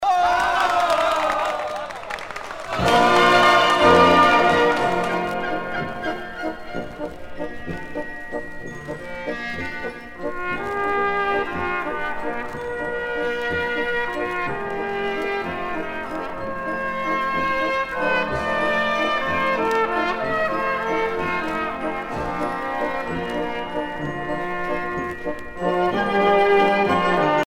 danse : valse viennoise
Pièce musicale éditée